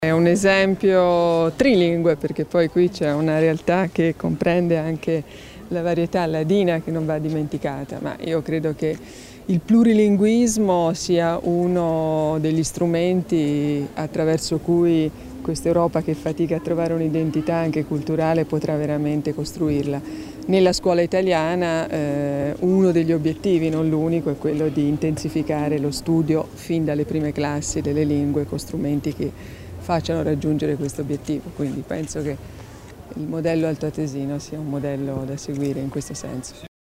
L'Assessore Tommasini illustra i dettagli dell'incontro con il Ministro Giannini